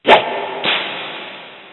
whip.mp3